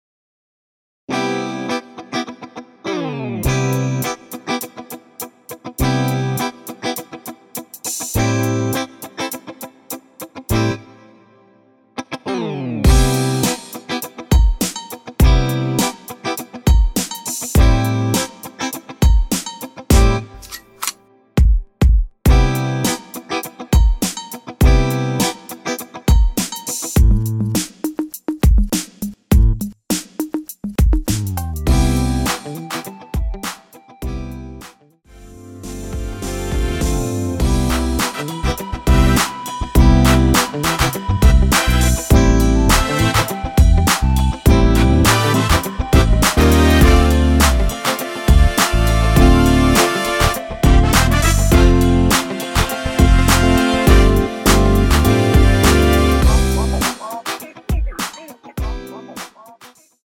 전주 없이 시작 하는 곡이라서 1마디 전주 만들어 놓았습니다.(미리듣기 확인)
3초쯤 노래 시작 됩니다.
원키에서(-1)내린 멜로디 포함된 MR입니다.(미리듣기 확인)
앞부분30초, 뒷부분30초씩 편집해서 올려 드리고 있습니다.
중간에 음이 끈어지고 다시 나오는 이유는